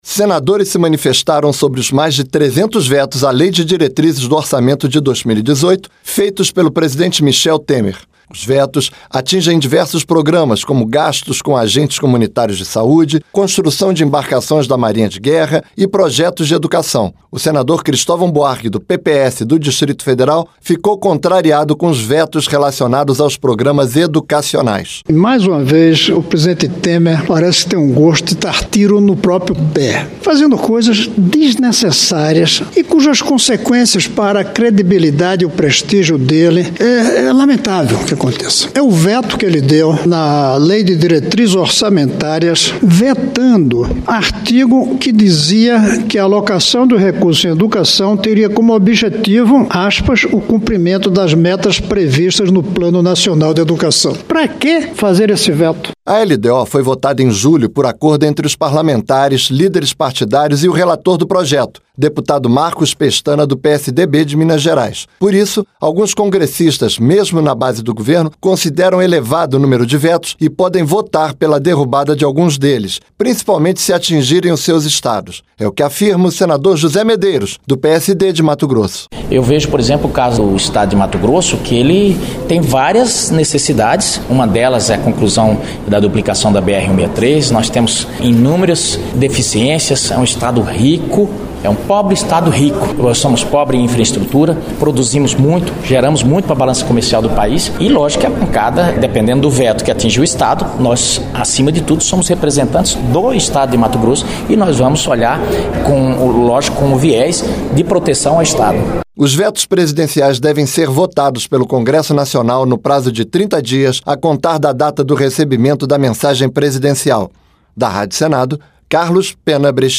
O senador Cristovam Buarque (PPS–DF) lamentou os vetos relacionados aos programas educacionais. O senador José Medeiros (PSD–MT), que integra a base parlamentar do governo, também considera elevado o número de vetos e pode votar pela derrubada de alguns deles, principalmente se atingirem o seus estado.